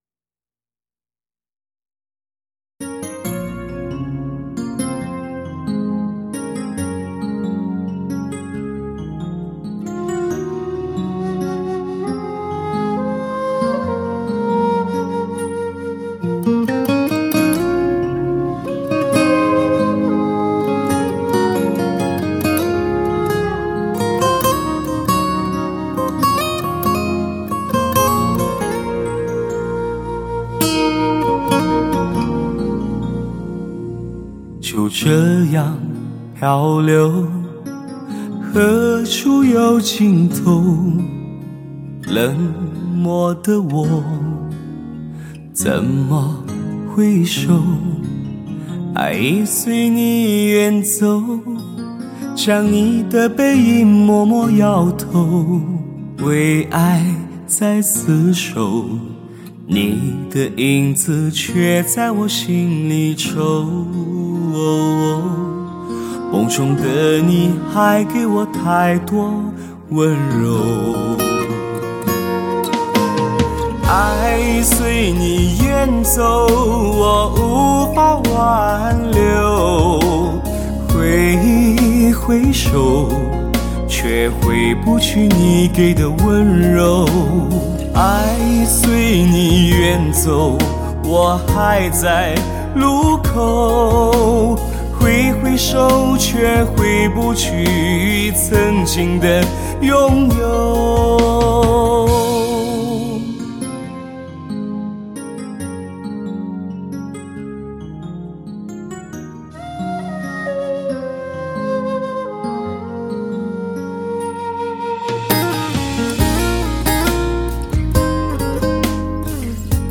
极致惊艳男声情歌典范，华语乐坛五男声全新魅力演绎。